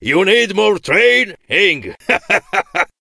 chuck_kill_vo_04.ogg